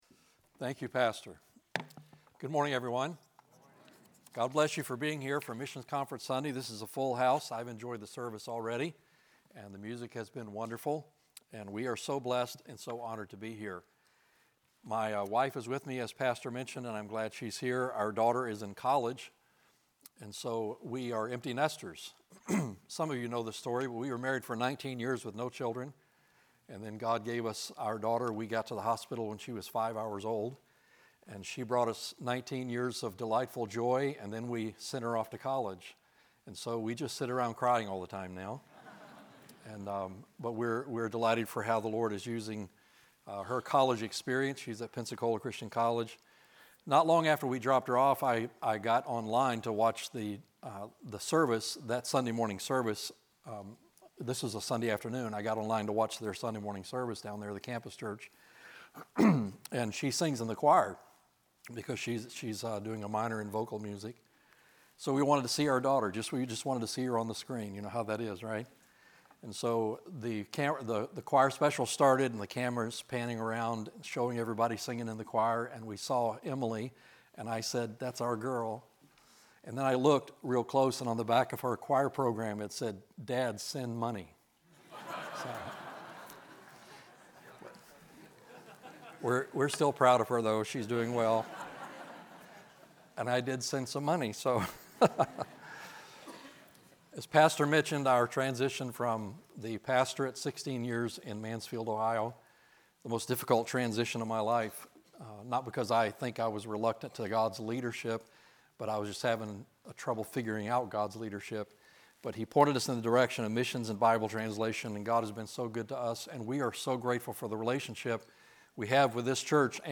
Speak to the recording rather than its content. Sermon from the pulpit of Falls Baptist Church